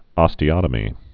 (ŏstē-ŏtə-mē)